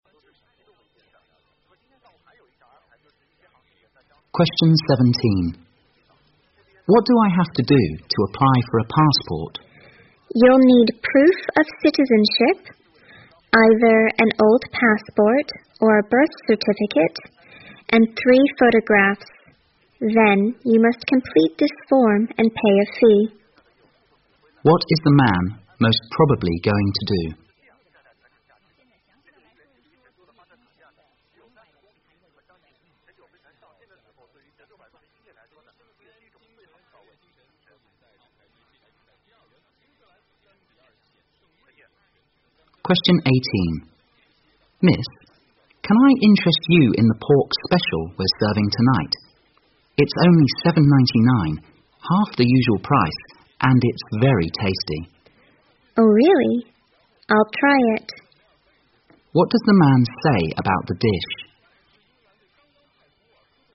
在线英语听力室019的听力文件下载,英语四级听力-短对话-在线英语听力室